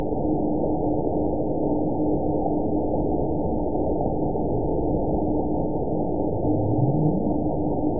event 920088 date 02/22/24 time 07:35:59 GMT (1 year, 2 months ago) score 9.45 location TSS-AB02 detected by nrw target species NRW annotations +NRW Spectrogram: Frequency (kHz) vs. Time (s) audio not available .wav